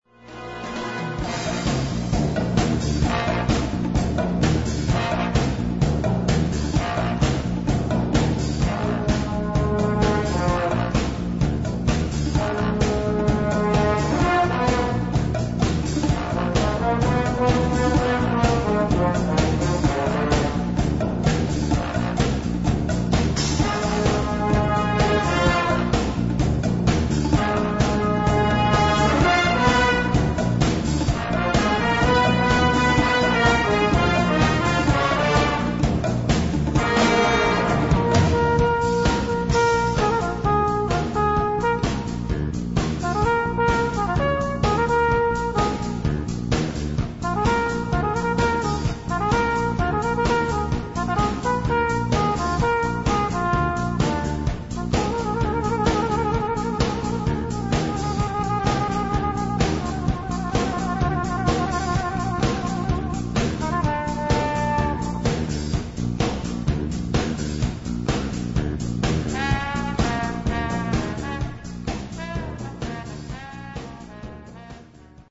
Gattung: Filmmusik
Besetzung: Blasorchester